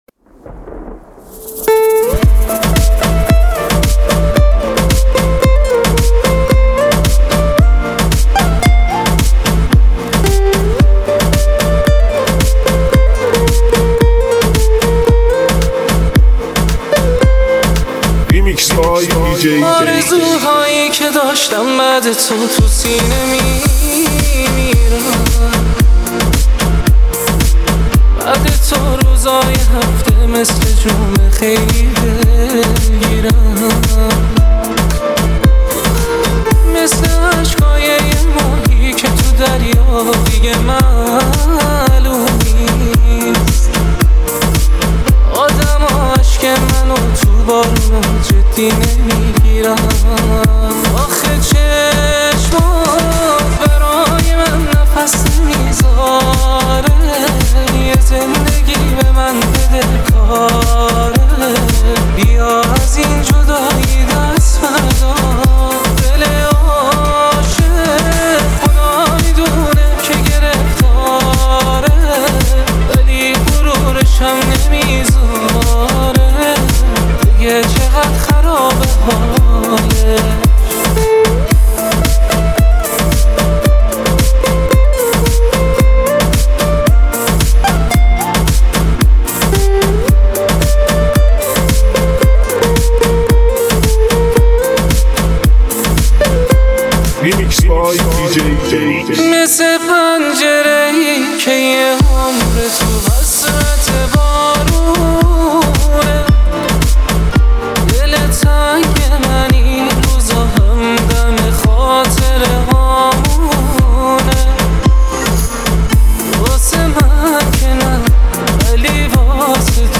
بیس دار MP3heheshmat